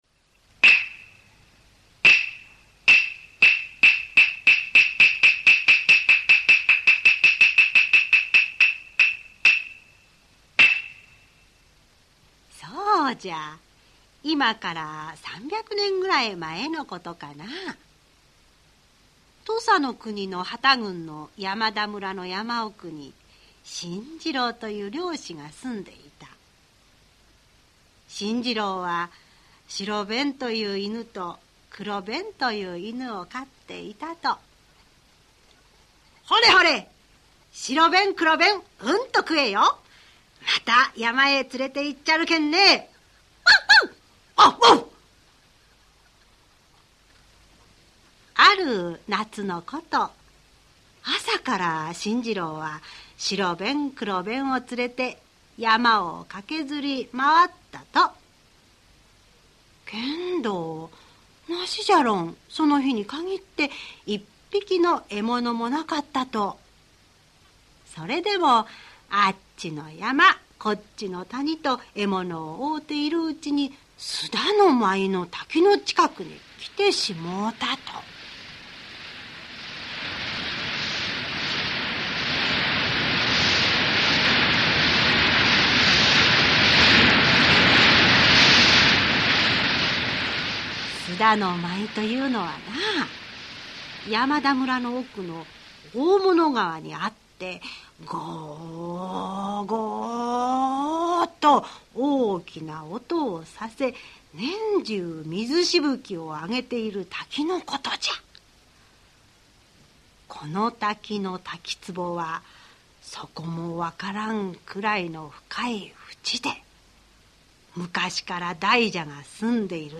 [オーディオブック] 白べん 黒べん